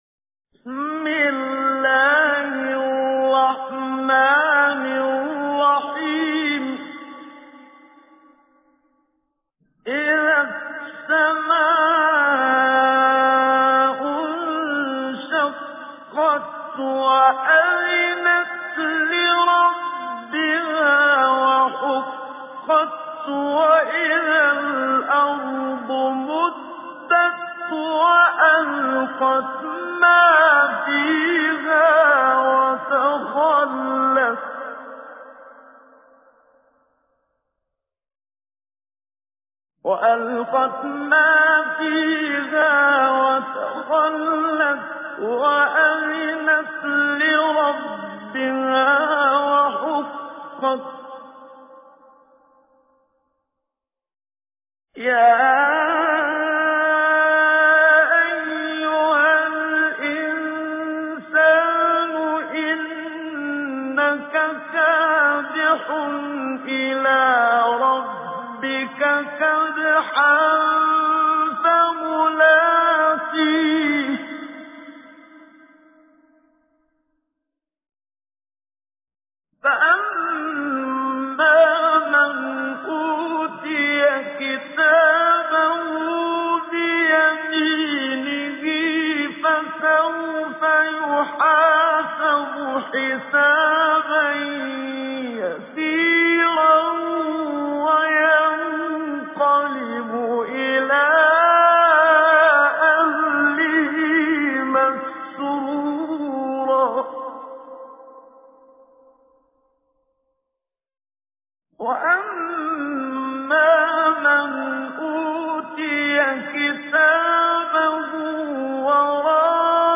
تجويد
سورة الانشقاق الخطیب: المقريء الشيخ محمد صديق المنشاوي المدة الزمنية: 00:00:00